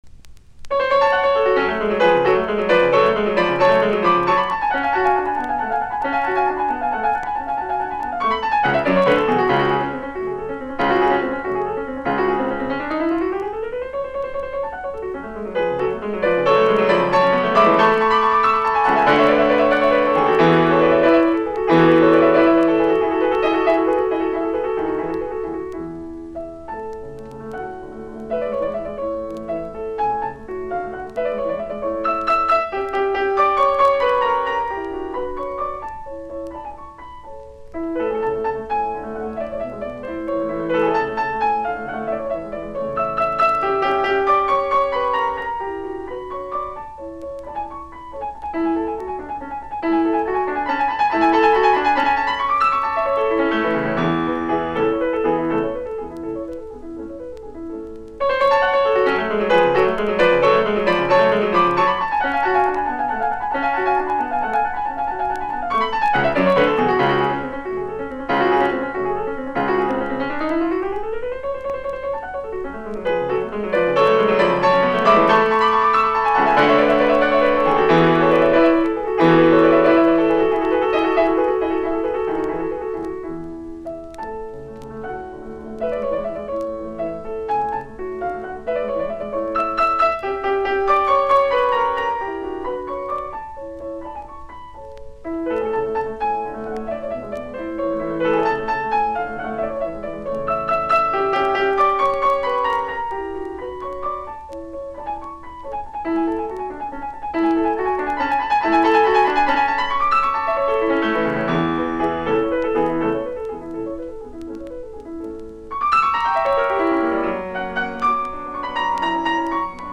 Presto
Horowitz, Vladimir ( piano ) Horowitz plays Clementi sonatas.
Soitinnus: Piano.